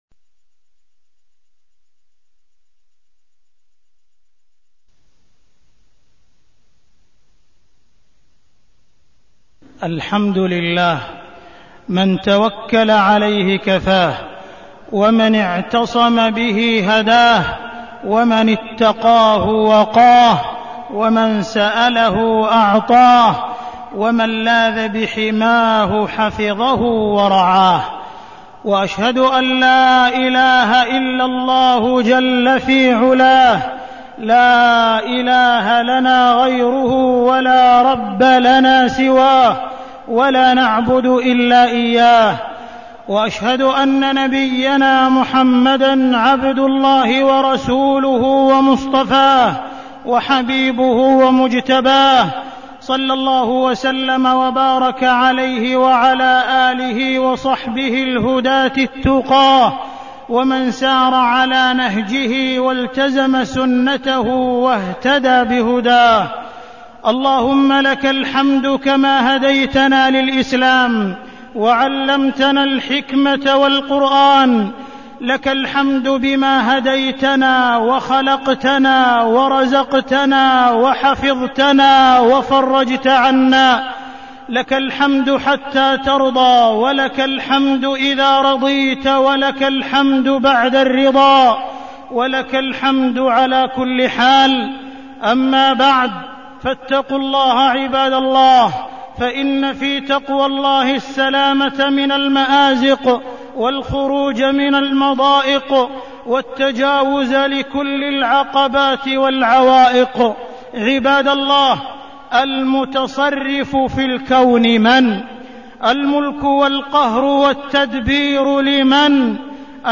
تاريخ النشر ١ صفر ١٤١٨ هـ المكان: المسجد الحرام الشيخ: معالي الشيخ أ.د. عبدالرحمن بن عبدالعزيز السديس معالي الشيخ أ.د. عبدالرحمن بن عبدالعزيز السديس التوكل على الله